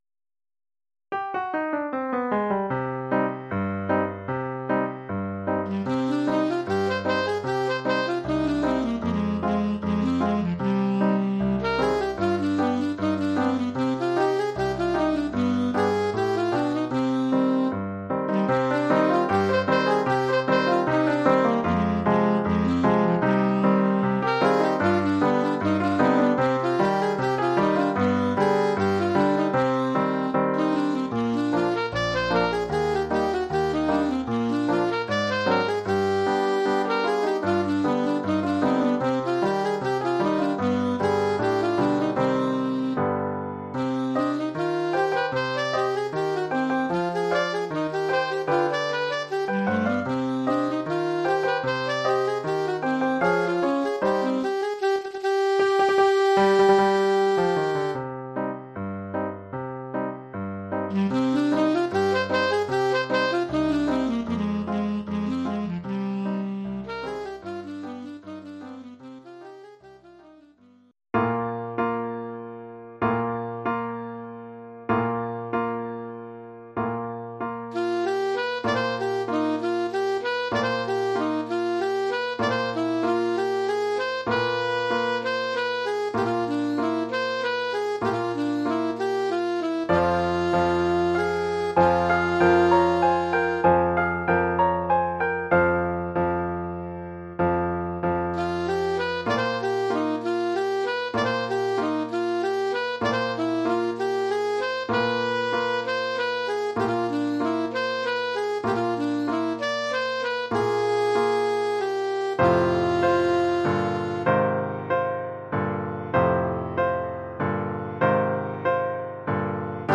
Oeuvre pour saxophone alto et piano.